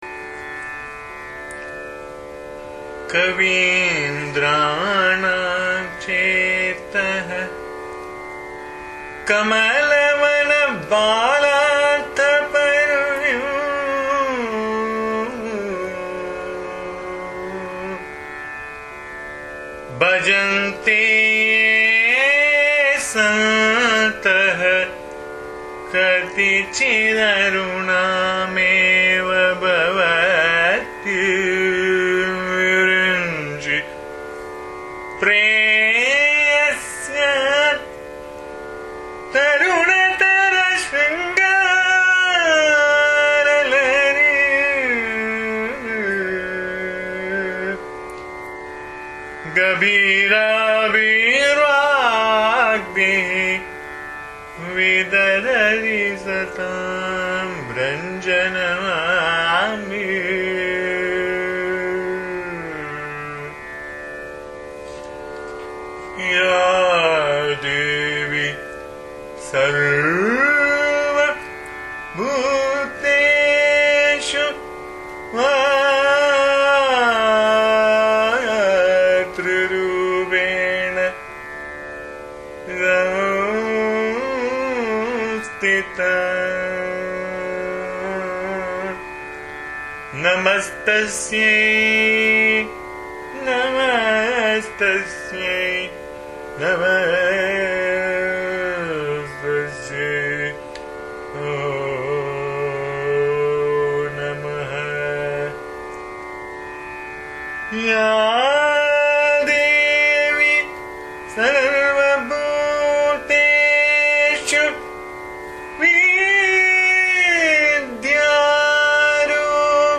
This is a very beautiful pure malayalam song which is a cry of love and devotion by a devotee about wanting to attain the divine mother. The song is set in Neelambari Raga though slight traces of ananda bhairavi creeps in (unless sung very properly).